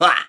One of Ludwig's voice clips in New Super Mario Bros. Wii